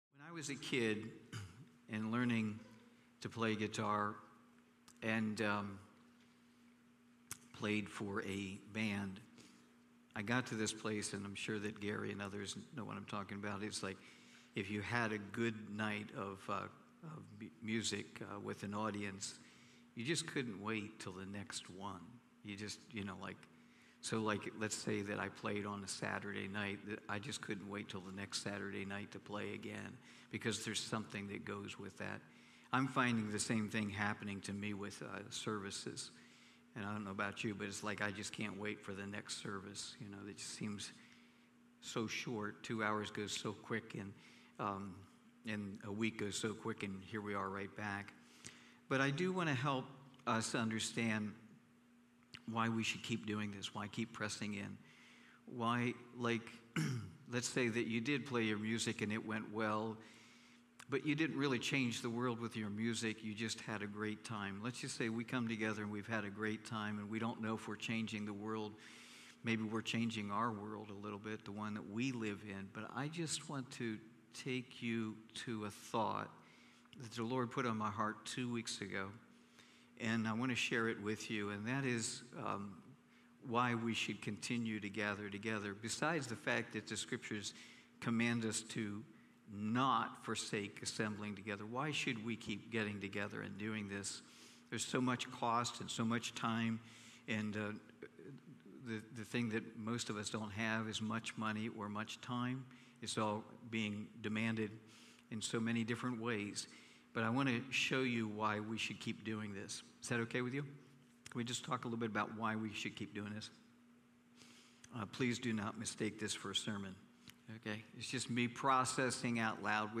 Cornerstone Fellowship Sunday morning, livestreamed from Wormleysburg, PA.